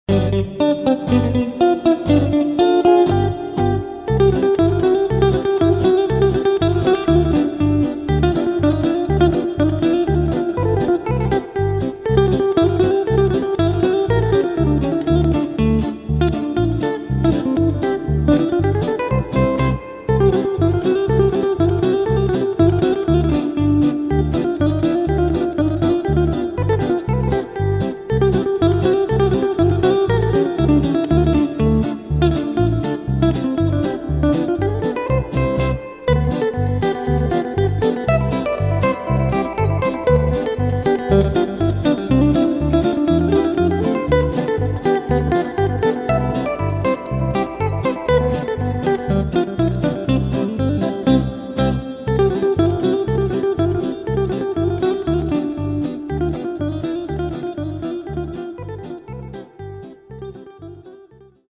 Polka per chitarra   PDF